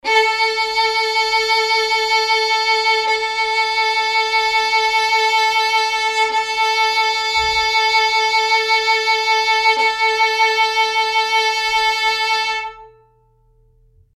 samples / violin / A4.mp3